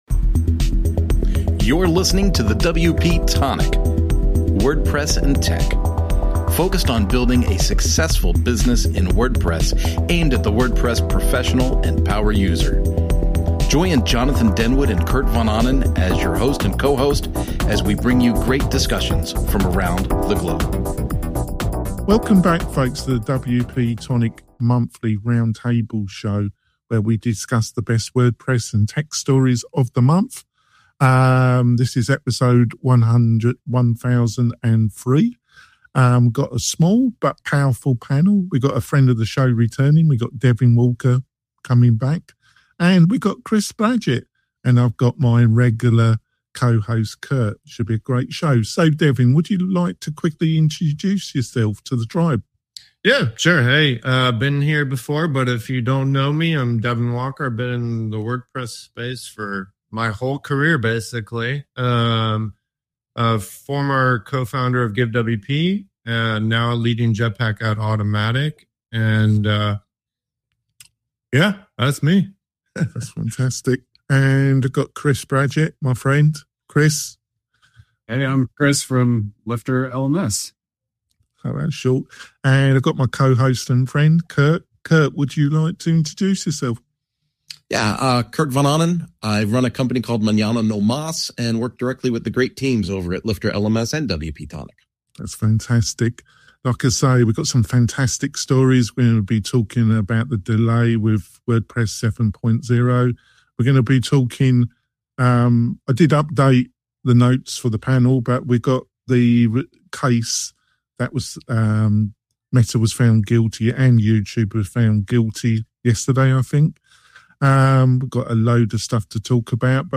#916 - WP-Tonic This Month in WordPress & Tech Live Round Table Show
Don't miss out on this week's live discussion! Join us on Thursday as we delve into the newest WordPress and tech articles that have been making waves this month.